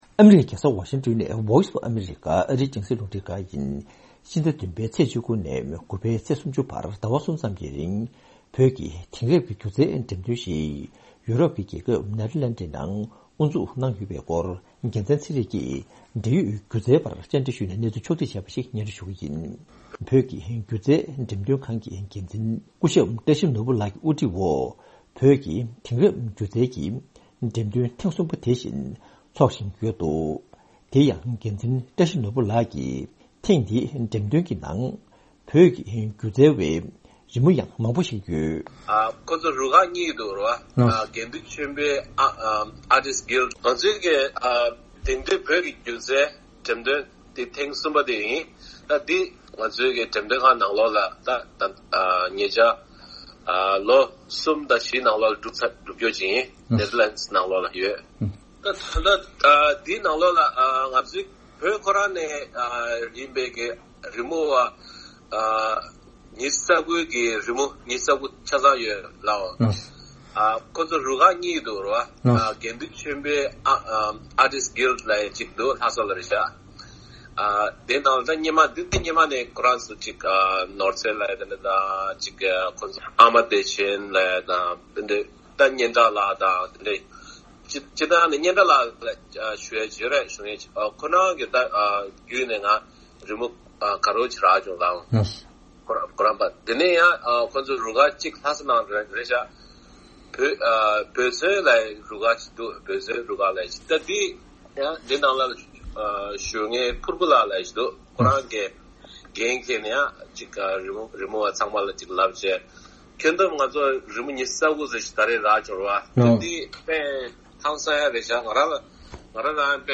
འབྲེལ་ཡོད་སྒྱུ་རྩལ་བ་ཞིག་ལ་འགྲེམ་སྟོན་སྐོར་བཅར་འདྲི་ཞུས་ནས་གནས་ཚུལ་ཕྱོགས་བསྒྲིགས་བྱས་པ་ཞིག་སྙན་སྒྲོན་ཞུ་ཡི་རེད།།